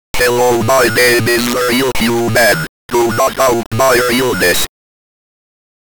The origin of the song title, a C64 SAM recording made in 2017.